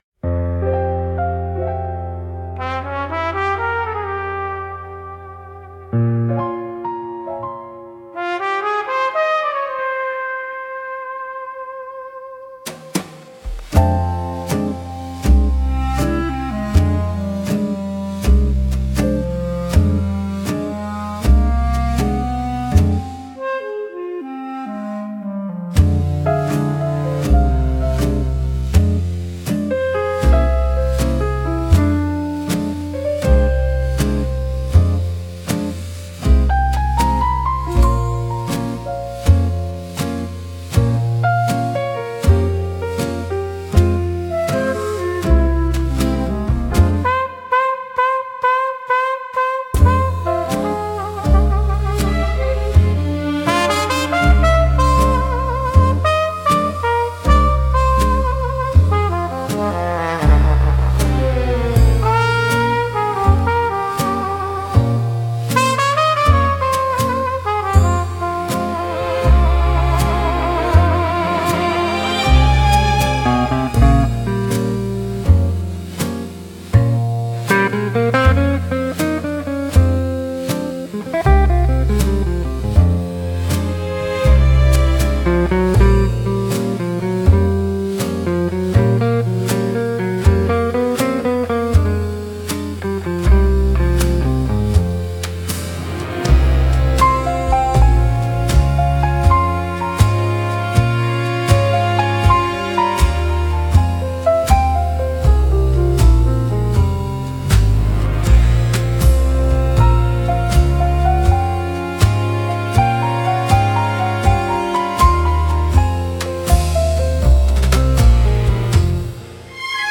música e arranjo: IA) instrumental 10